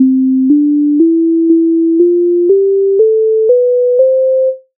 гама до мажор
test_hama_do_mazhor.mp3